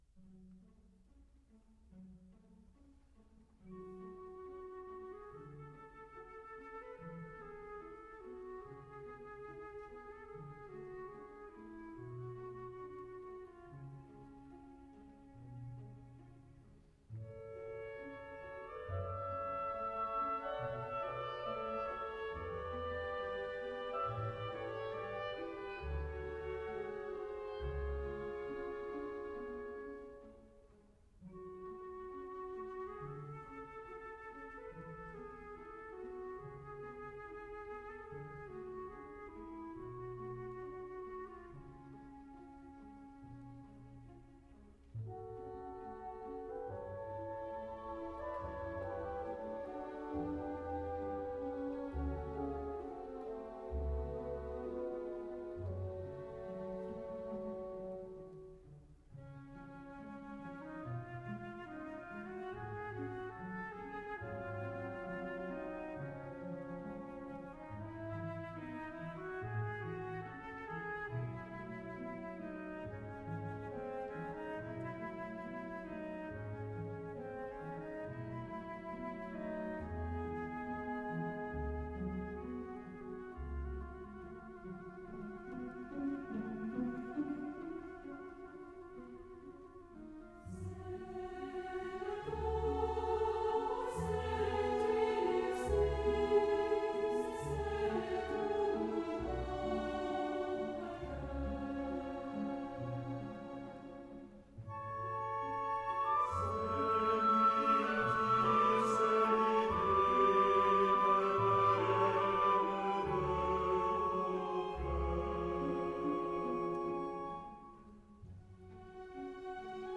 Pavanechoeurs.mp3